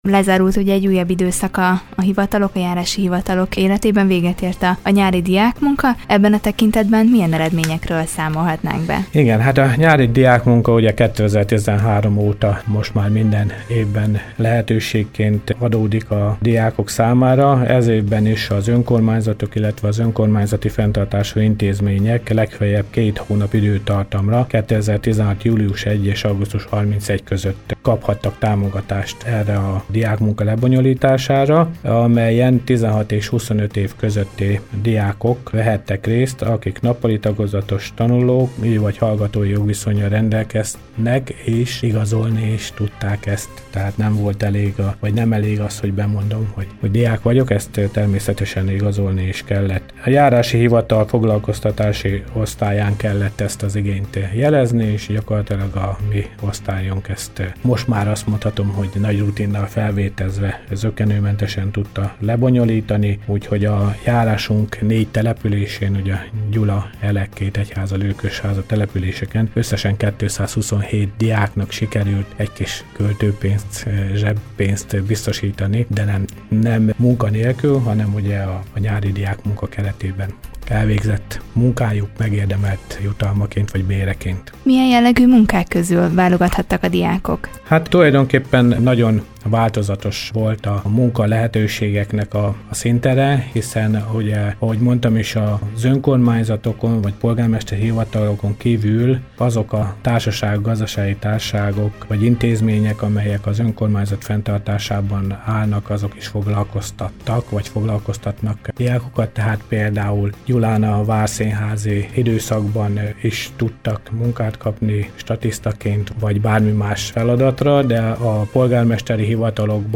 Dr. Gulyás Imre, a Gyulai Járási Hivatal vezetője volt a Körös Hírcentrum stúdiójának vendége. Vele beszélgetett tudósítónk az állami tisztviselőket érintő új törvényről, a Gyulai járást érintő ügyekről, valamint a nyári diákmunka programról